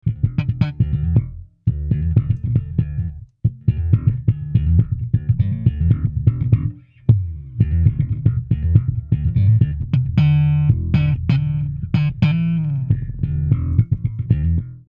7バンド仕様でベースサウンドの細かなイコライジングが可能です。